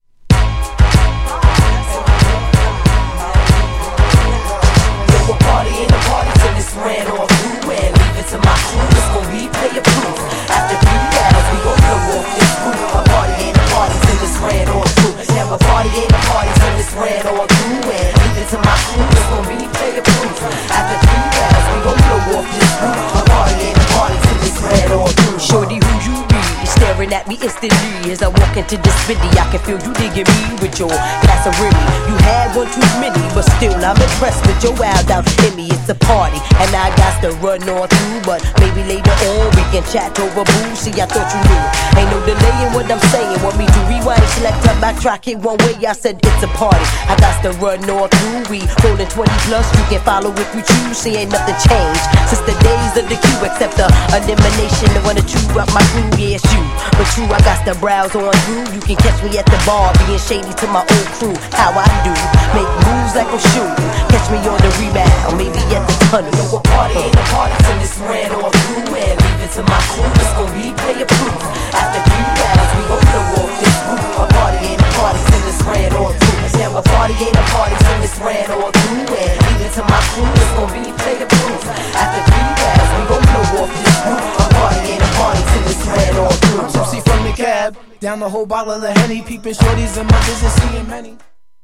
GENRE Hip Hop
BPM 101〜105BPM